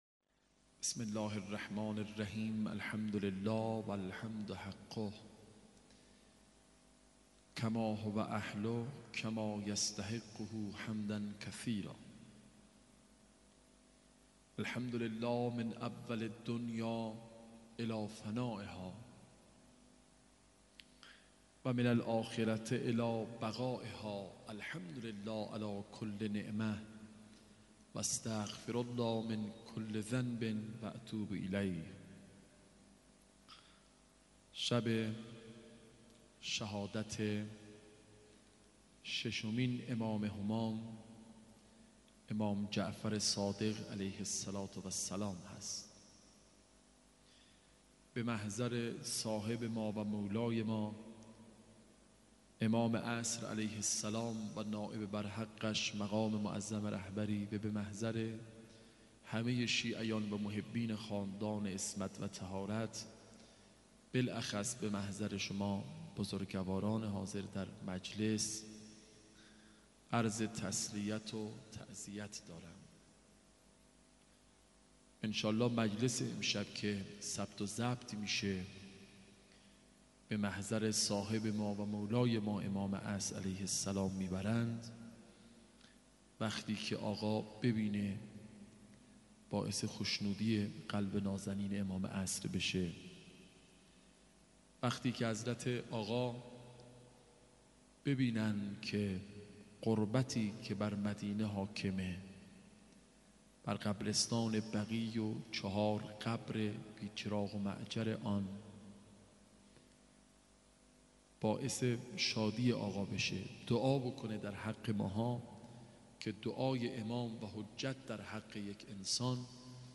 شهادت امام صادق (ع) هیئت الرضا (ع) بابلسر